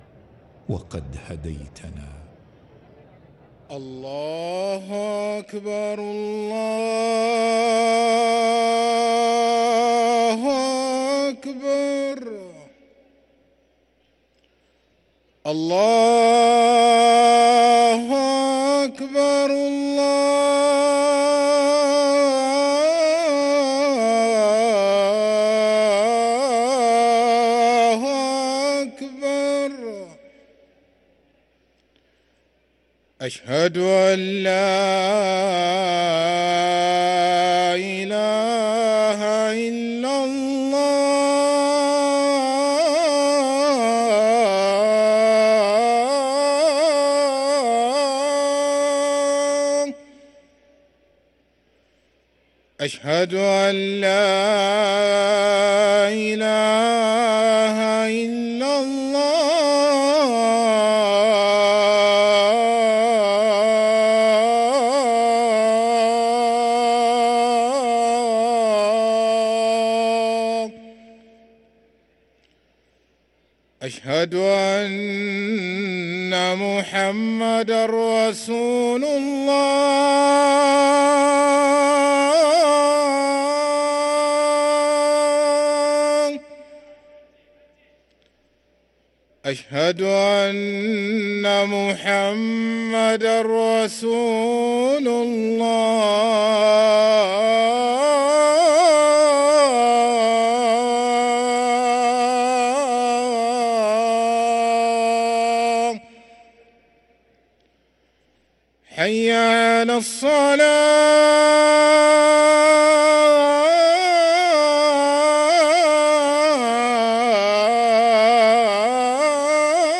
أذان العشاء للمؤذن سعيد فلاته السبت 12 شعبان 1444هـ > ١٤٤٤ 🕋 > ركن الأذان 🕋 > المزيد - تلاوات الحرمين